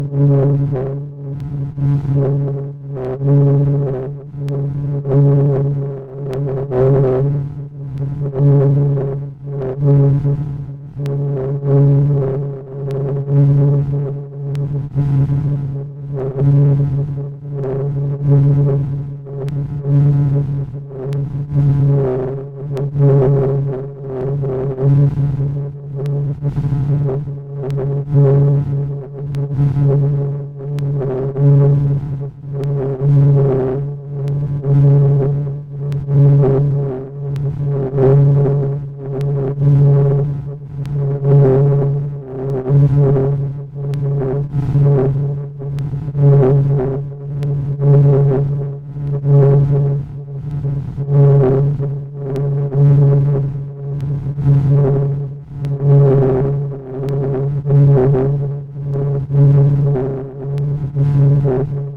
Modular noises